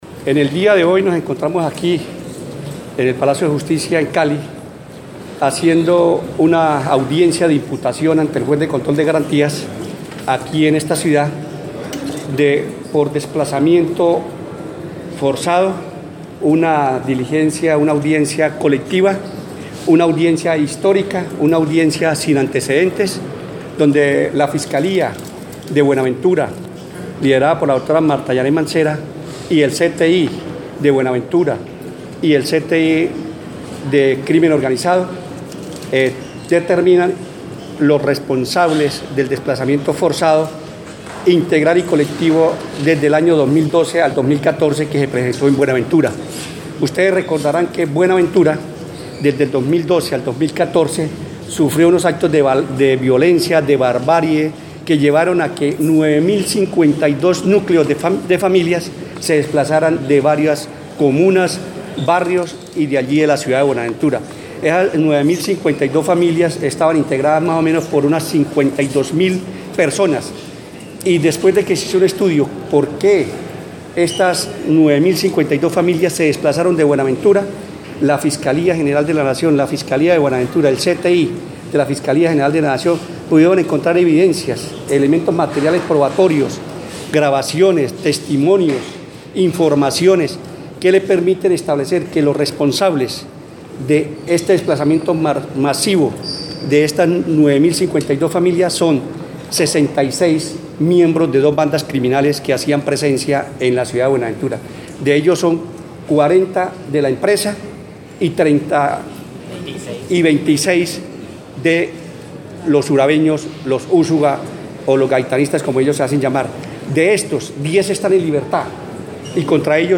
Rueda de prensa Luis González León, Director Nacional de Seccionales y Seguridad Ciudadana
Lugar: Palacio de justicia de Cali (Valle del Cauca)